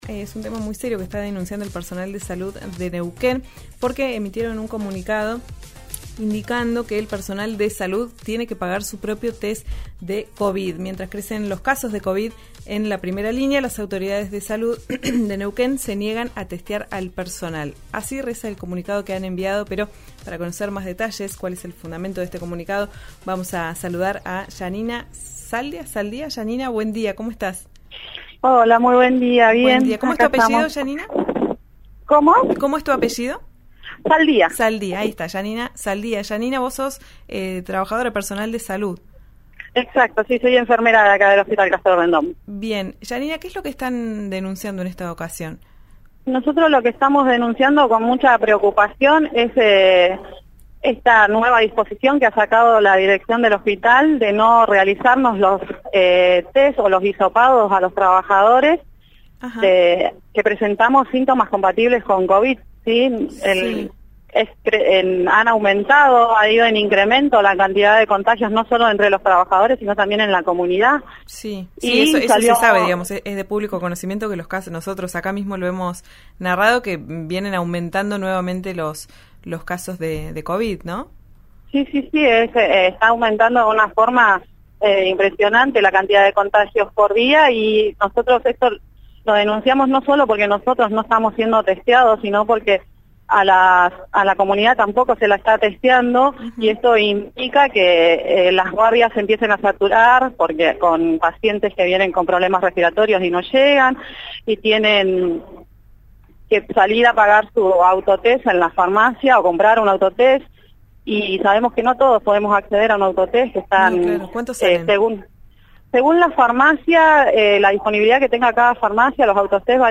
Sintonizá RÍO NEGRO RADIO.